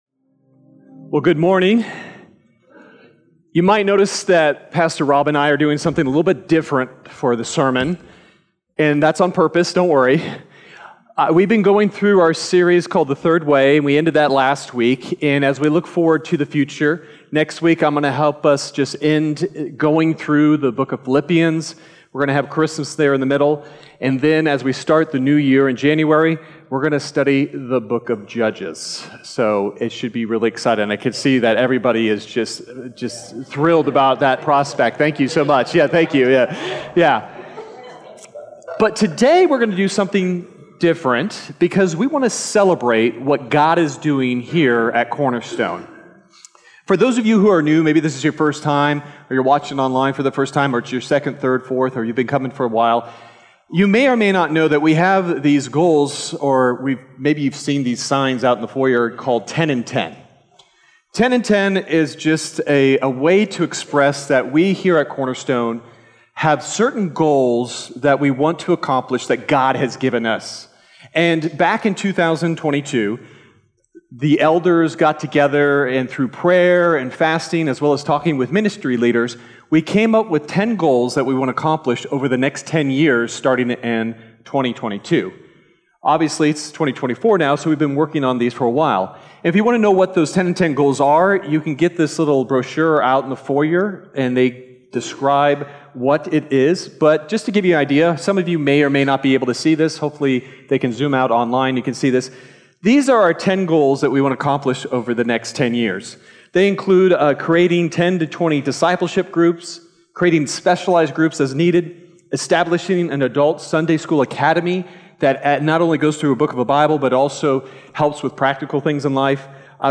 Sermon Detail
December_8th_Sermon_Audio.mp3